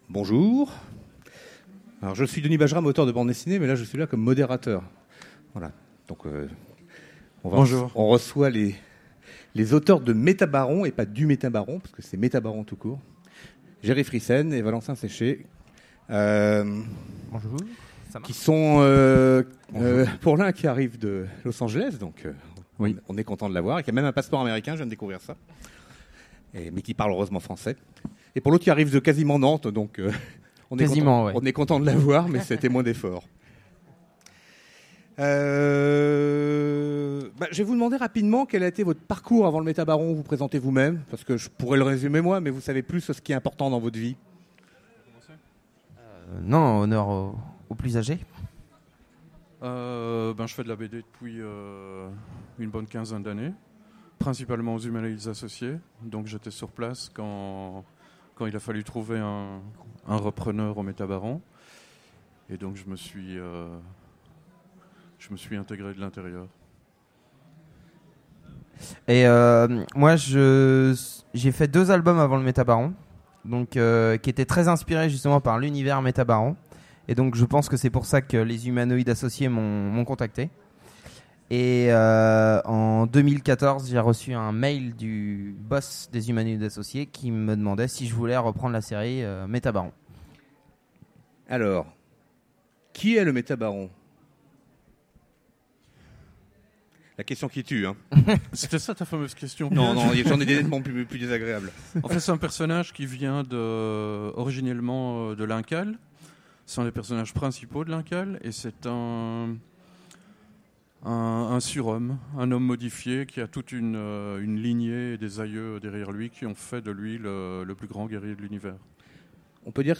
Utopiales 2017 : Conférence Les MétaBarons, une BD générationnelle